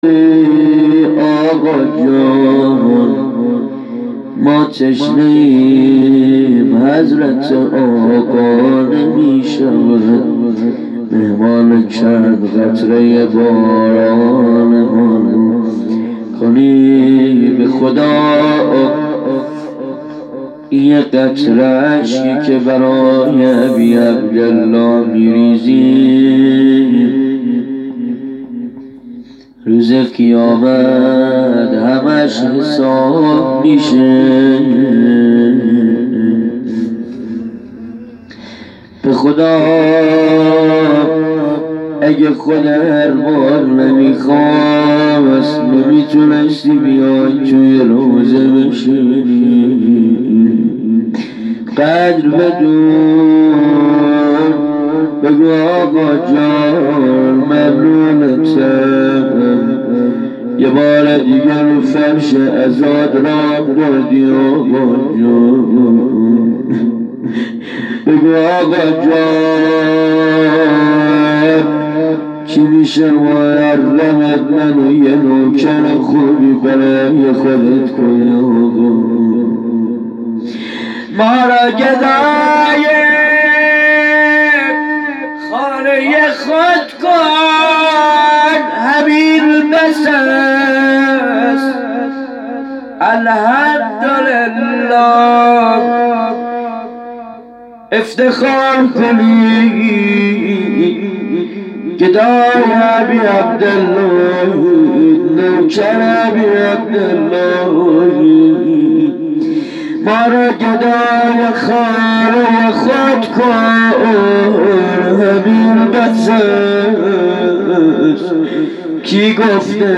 مراسم ایام محرمالحرام